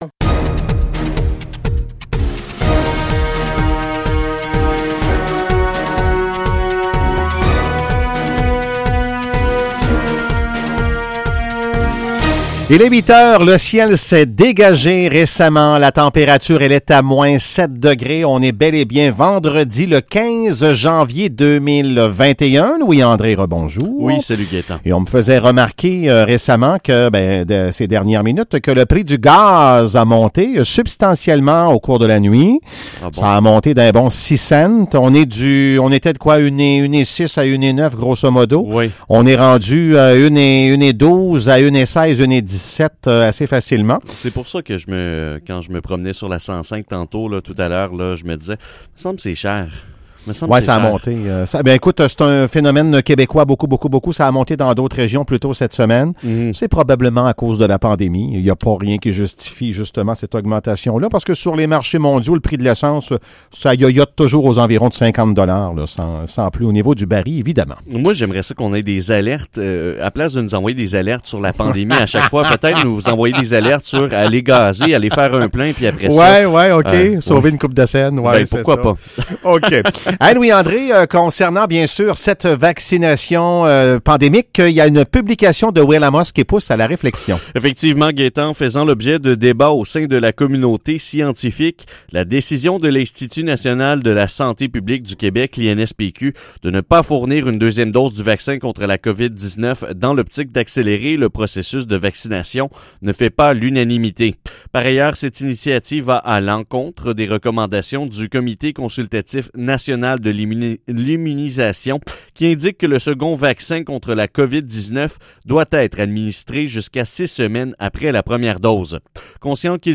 Nouvelles locales - 15 janvier 2021 - 8 h